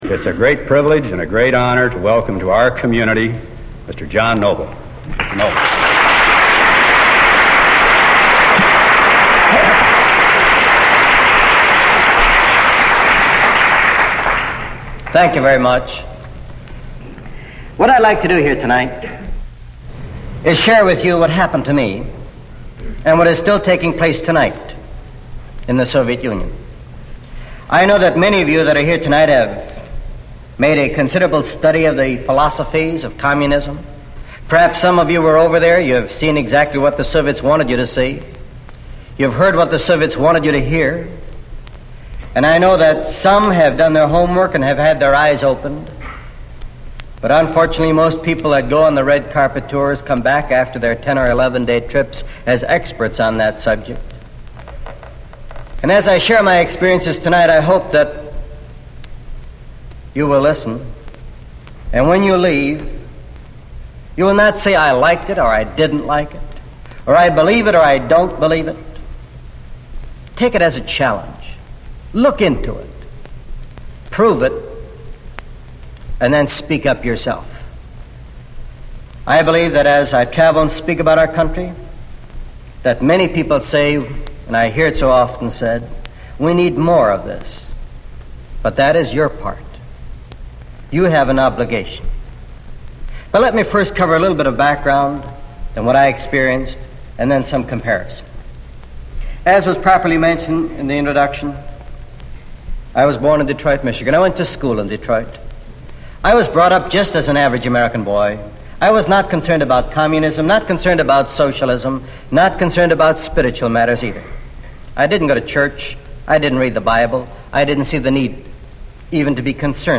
Rede aus dem Jahr 1957 im Originalton (englisch)